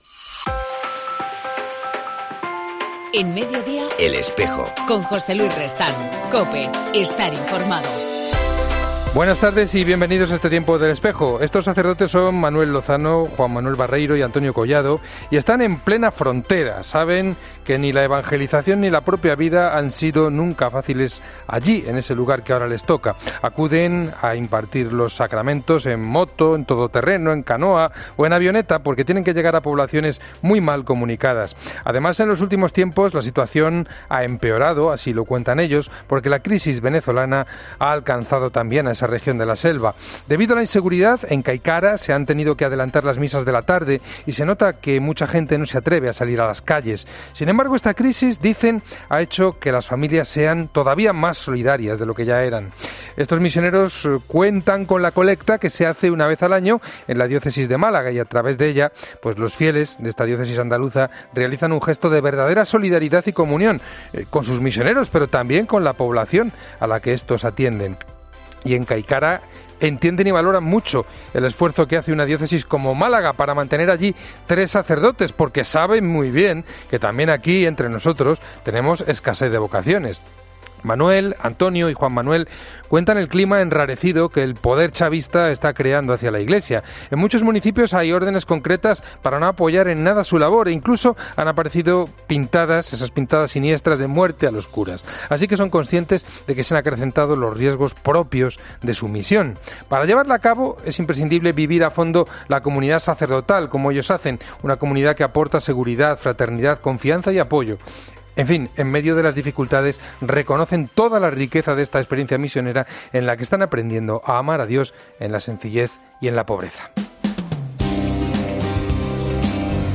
En El Espejo del 3 de mayo entrevistamos con la religiosa